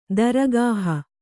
♪ daragāha